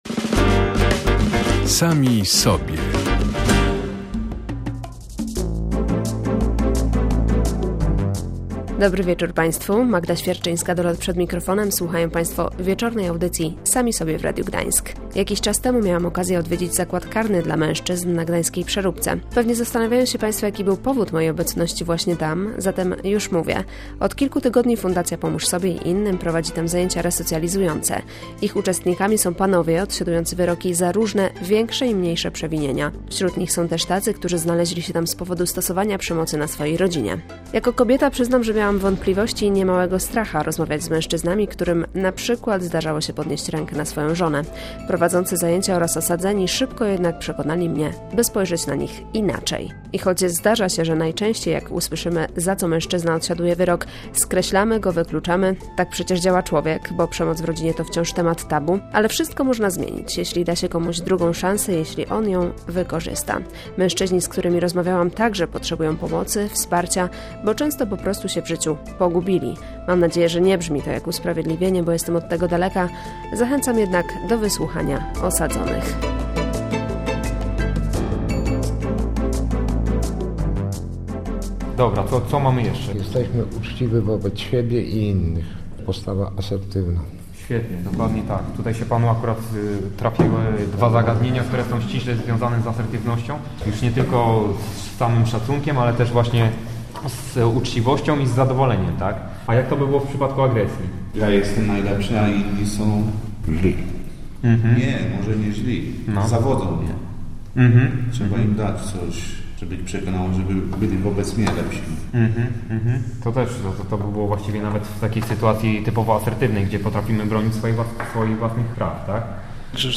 Gośćmi wieczornej audycji „Sami Sobie” w Radiu Gdańsk byli więźniowie z zakładu karnego na gdańskiej Przeróbce.